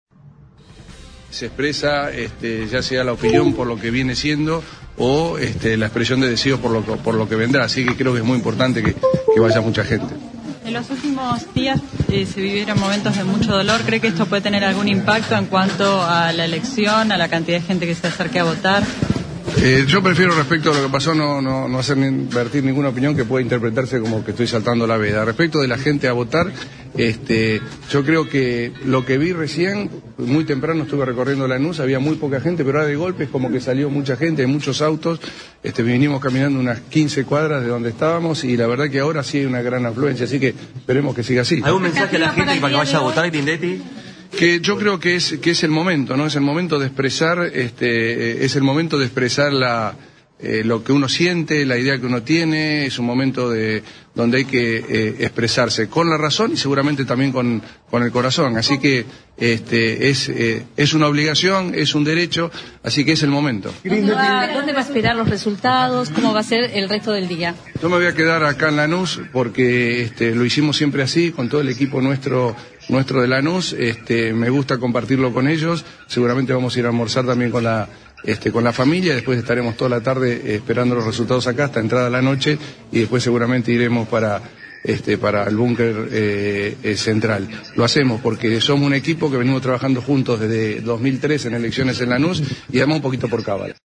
Luego de emitir su voto en la Escuela Primaria N° 28, Grindetti atendió a los medios de comunicación presentes y declaró: ” El voto es un derecho y una obligación que todos debemos ejercer, es el momento oportuno donde tienen que expresar lo que opina el vecino con la razón y el corazón sobre el futuro del país y la provincia, esperemos que sea una jornada en paz y donde la gente pueda expresarse masivamente”.